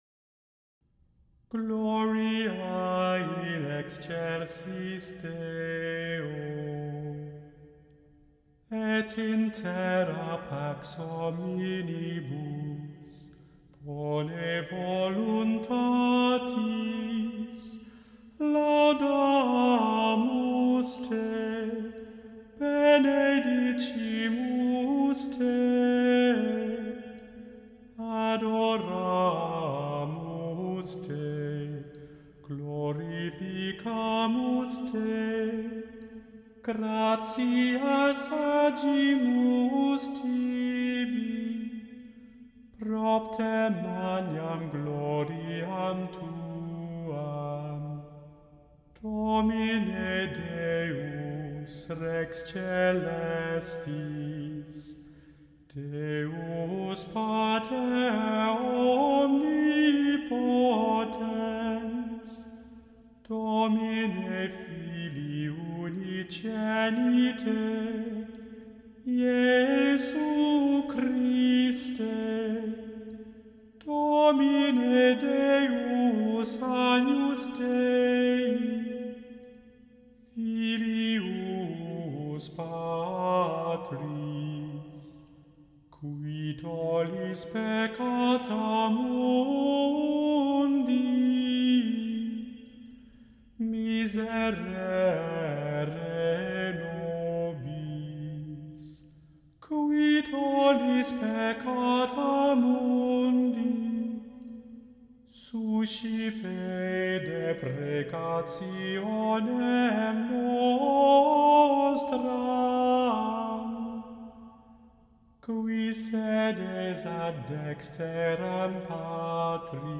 The Chant Project – Gloria (Missa de Angelis) – Immanuel Lutheran Church, New York City